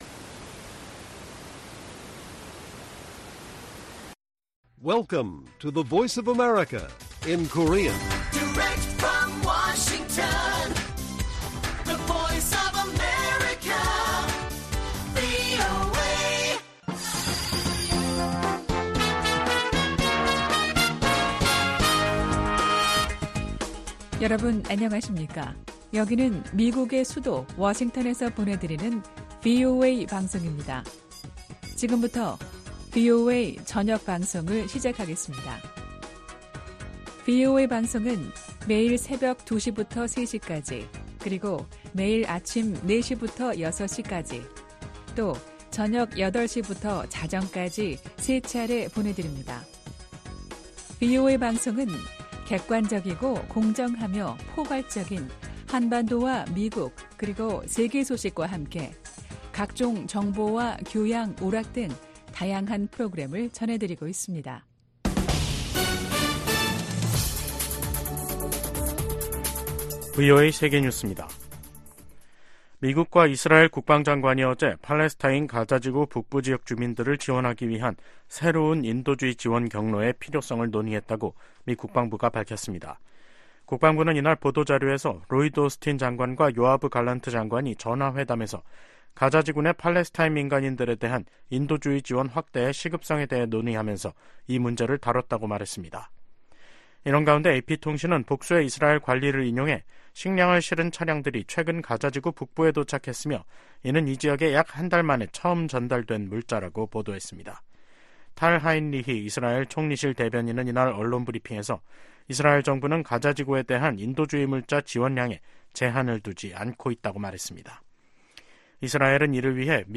VOA 한국어 간판 뉴스 프로그램 '뉴스 투데이', 2024년 2월 29일 1부 방송입니다. 미국과 한국의 외교장관들이 워싱턴 D.C.에서 만나 세계의 거의 모든 도전에 공조하는 등 양국 협력이 어느 때보다 강력하다고 평가했습니다. 북한이 유엔 군축회의에서 국방력 강화 조치는 자위권 차원이라며 비난의 화살을 미국과 동맹에게 돌렸습니다. 세계 최대 식품 유통업체가 북한 강제 노동 동원 의혹을 받고 있는 중국 수산물 가공 업체 거래를 전격 중단했습니다.